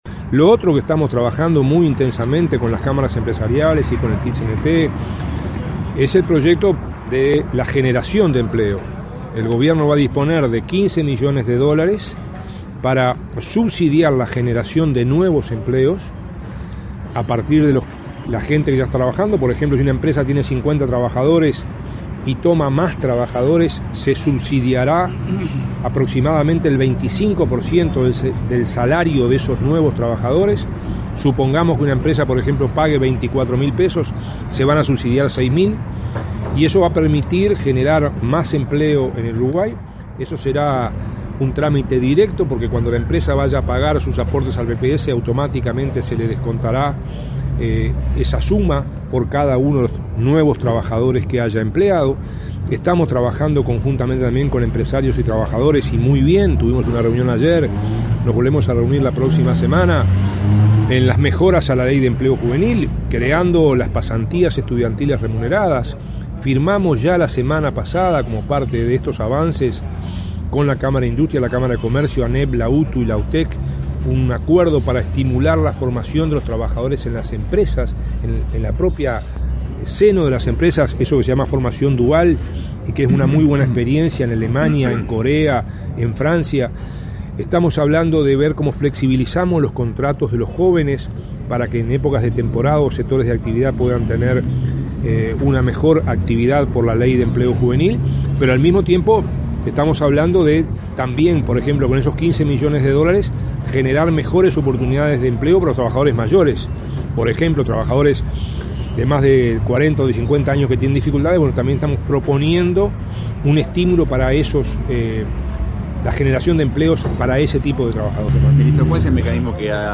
El ministro Ernesto Murro subrayó que el Gobierno dispondrá de 15 millones de dólares para generar nuevos empleos y estimular, entre otras medidas, el empleo para trabajadores mayores de 40 o 50 años. Tras su disertación en ADM, habló con la prensa de la formación dual de trabajadores y de la propuesta de prevención de conflictos presentada a empresarios y trabajadores en Consejos de Salarios.